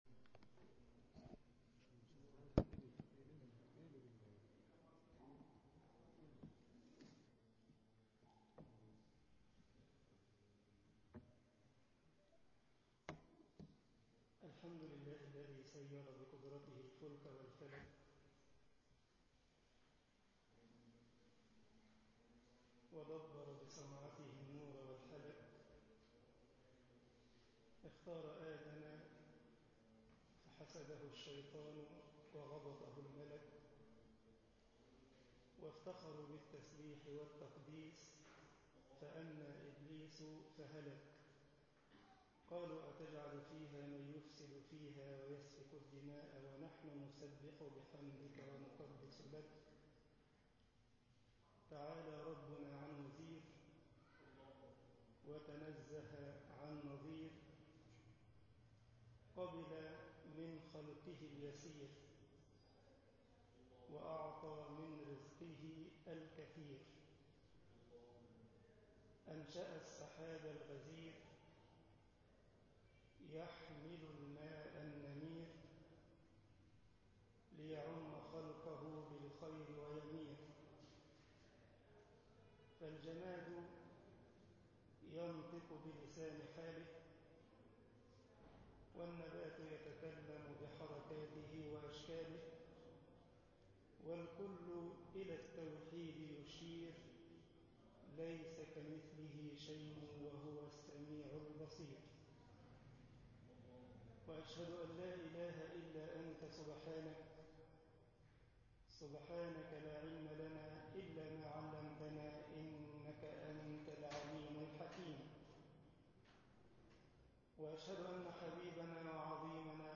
مسجد السلام العمرانية ـ الجيزة محاضرة
Muhadharat_awamer w nawahi nabawiya1_masjed al salam al omrania al jiza.mp3